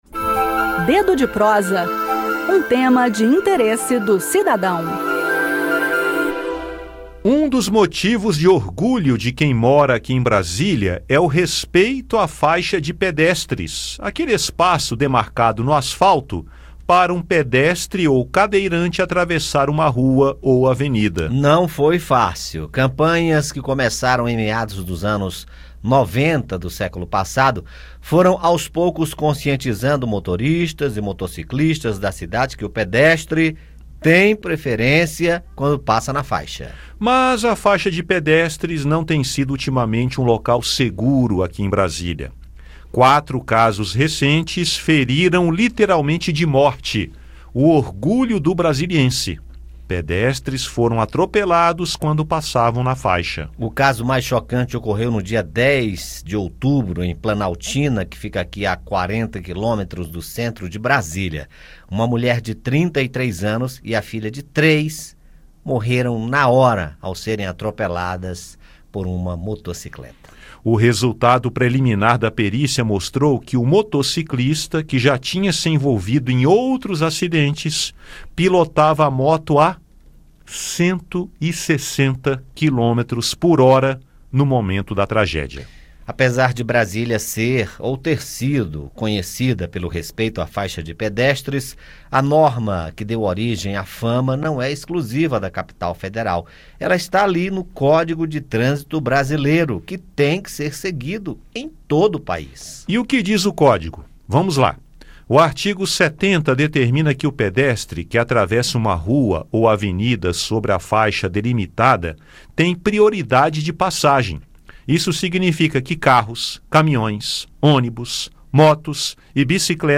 Ouça no bate-papo como manter a segurança ao atravessar a faixa e, ao dirigir